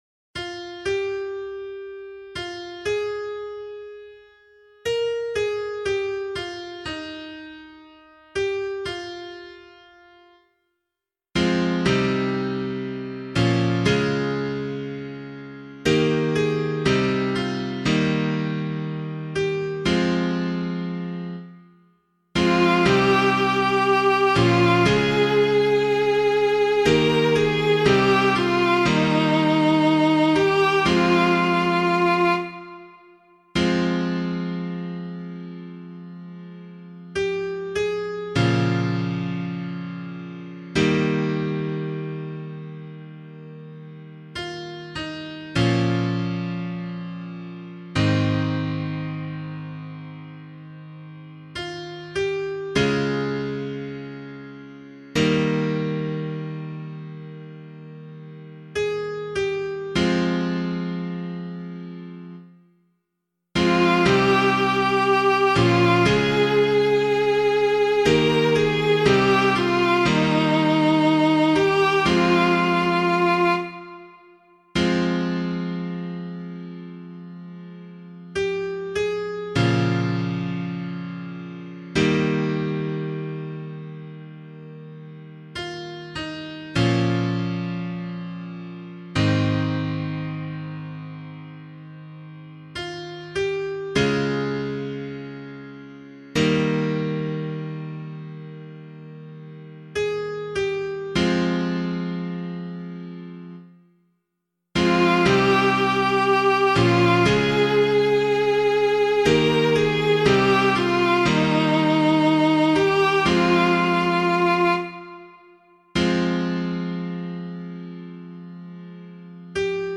018 Palm Sunday Psalm [LiturgyShare 2 - Oz] - piano.mp3